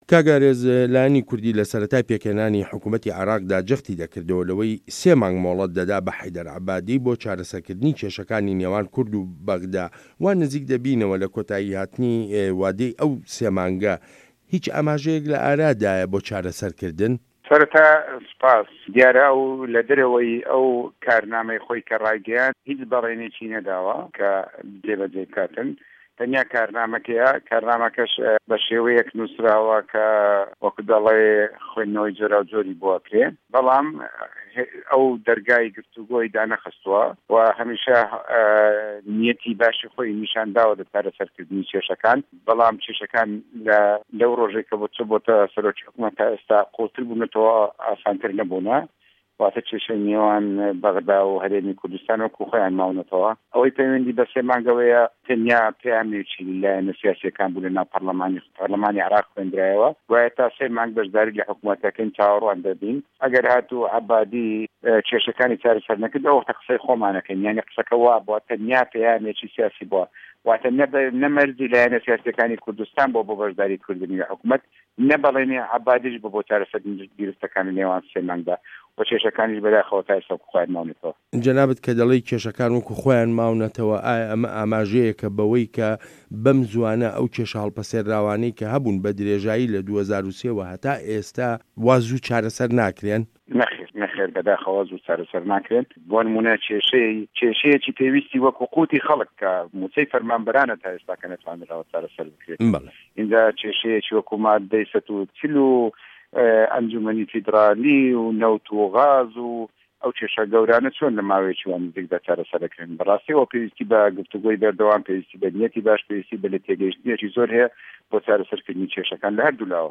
وتووێژ له‌گه‌ڵ ئارێز عه‌بدوڵا